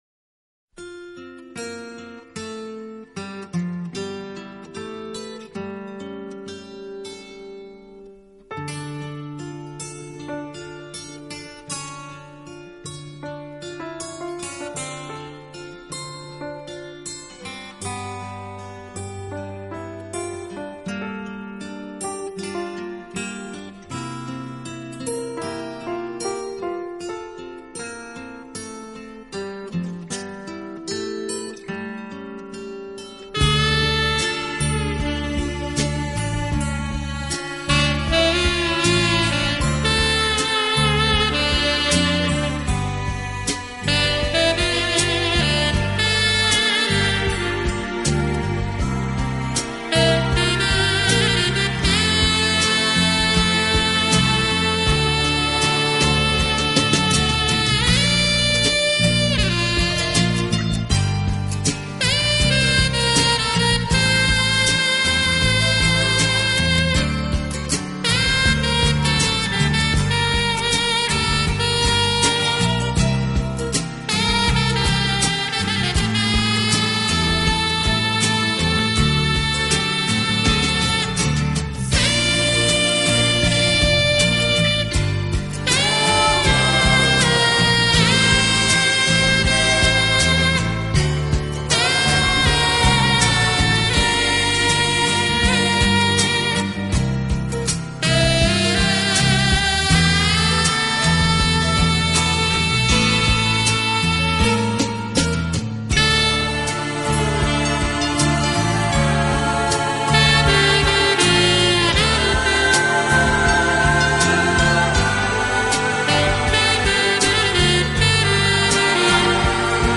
一直以来的灼热萨克斯演奏风格。